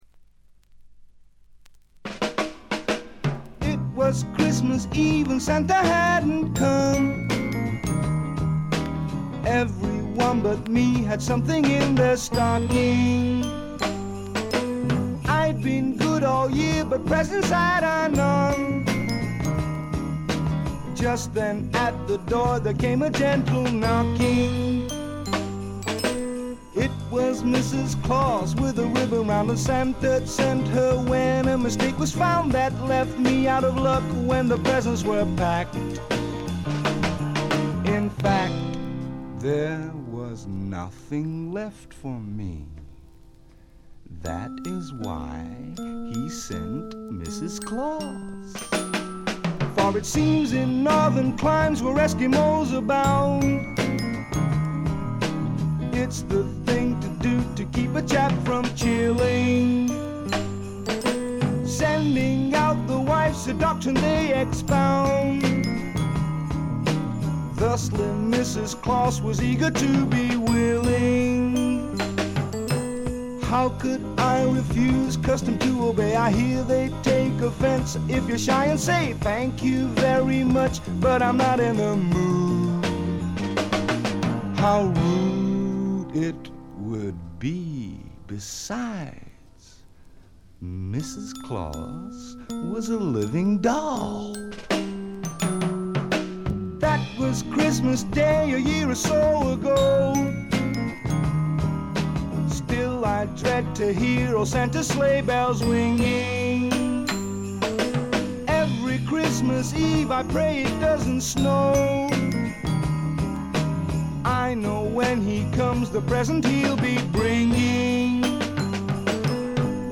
わずかなチリプチ。散発的なプツ音が数回。
試聴曲は現品からの取り込み音源です。